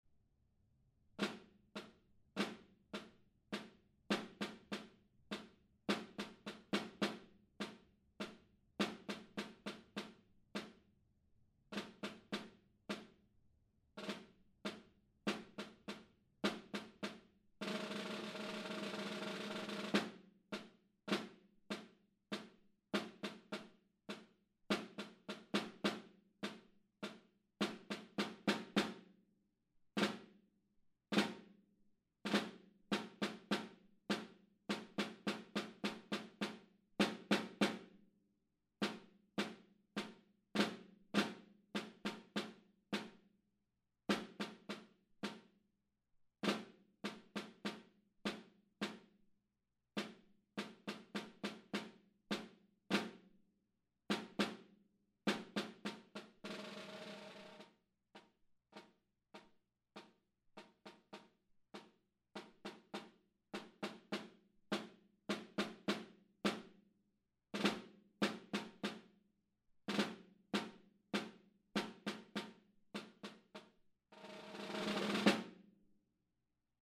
Encore 2026 Snare Drum Solos
Demo